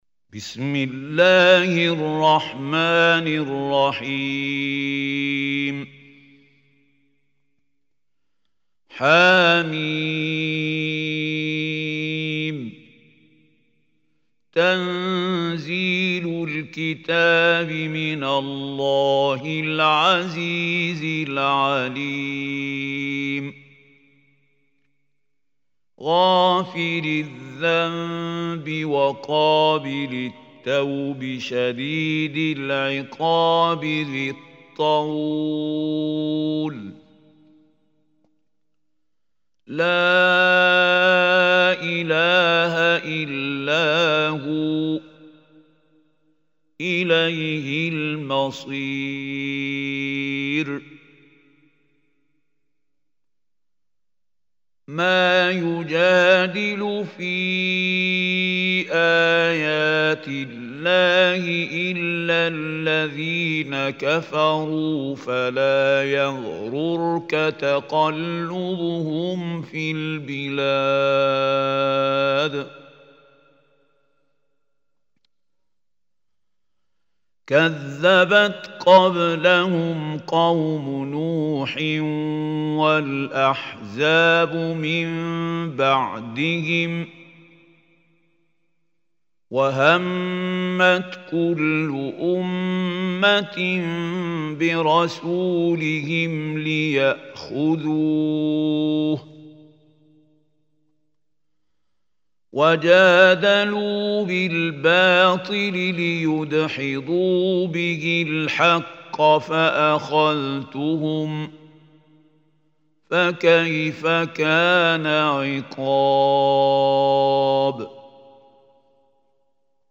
Surah Ghafir Recitation by Mahmoud Khalil Hussary
Surah Ghafir is 40 surah of Quran. Listen or play online mp3 tilawat / recitation in Arabic in the beautiful voice of Sheikh Mahmoud Khalil Al Hussary.